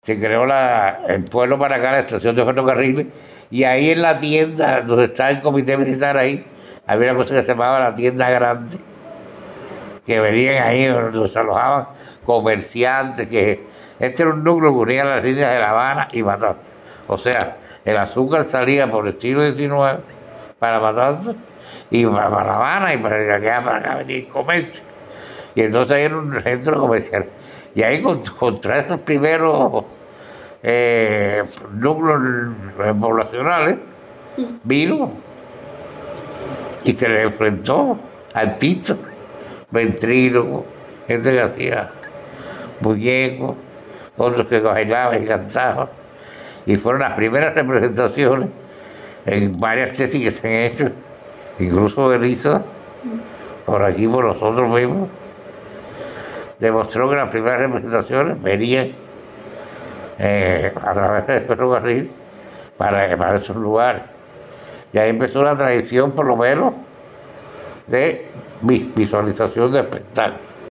Cultura Entrevista